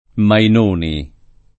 [ main 1 ni ]